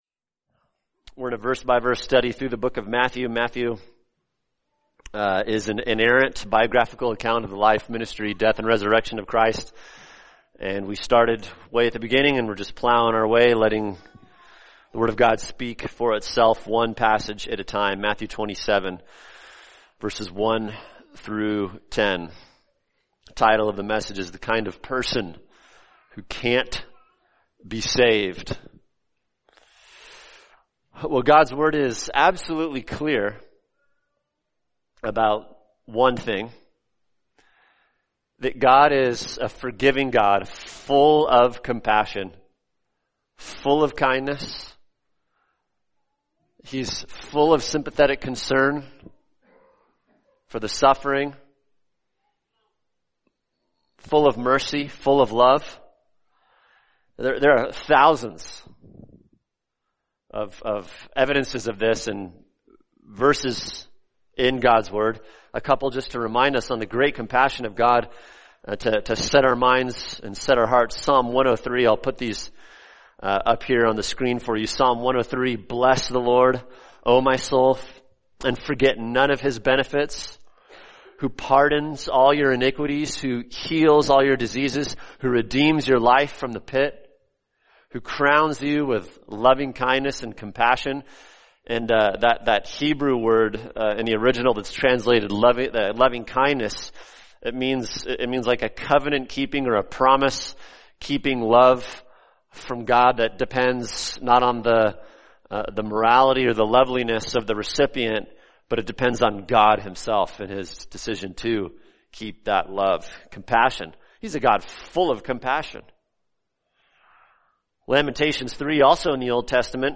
[sermon] Matthew 27:1-10 – The Kind of Person Who Can’t Be Saved | Cornerstone Church - Jackson Hole